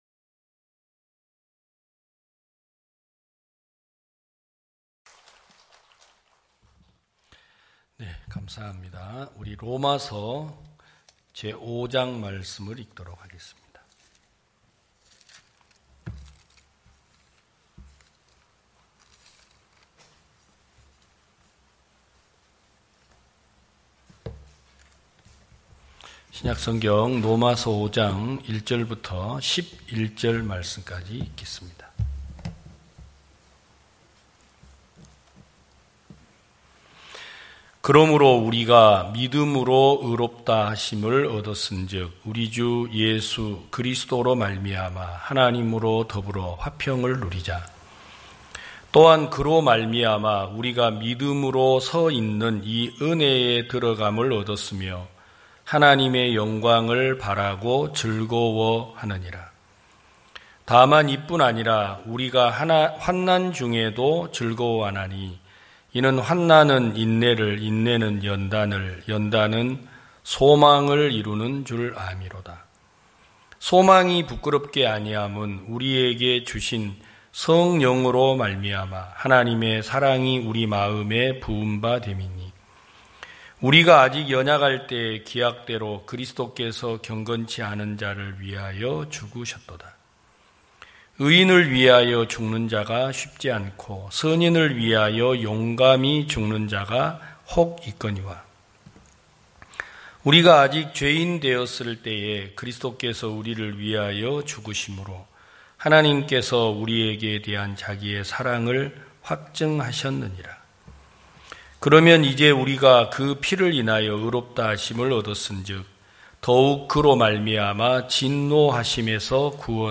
2021년 9월 19일 기쁜소식부산대연교회 주일오전예배
성도들이 모두 교회에 모여 말씀을 듣는 주일 예배의 설교는, 한 주간 우리 마음을 채웠던 생각을 내려두고 하나님의 말씀으로 가득 채우는 시간입니다.